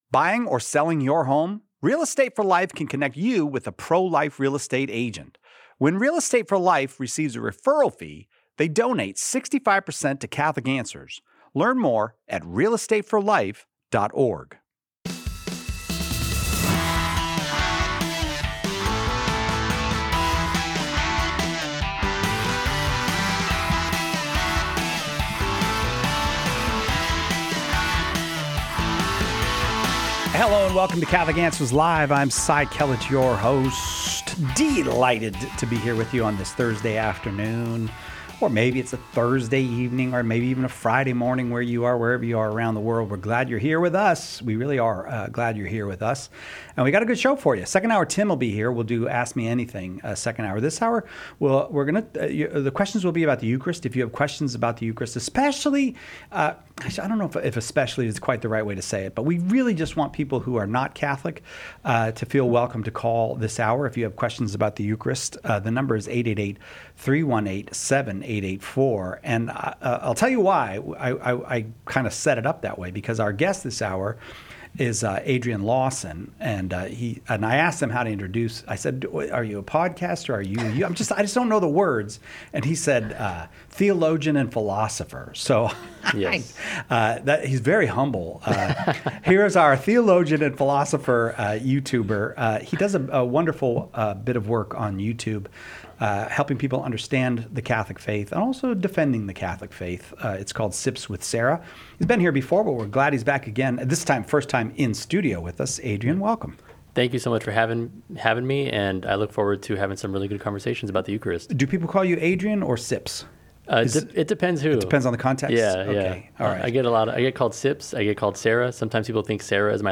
A caller enrolled in OCIA asks why we receive the Eucharist by eating it, and how that connects us to Christ’s sacrifice.